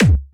b_kick_v127l8o5c.ogg